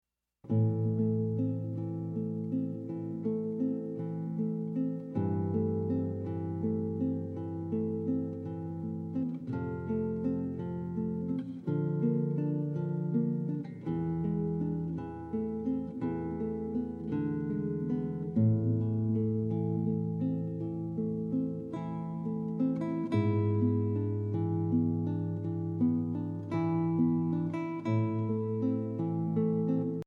Classical Guitar Fav Voicing: Guitar Method Level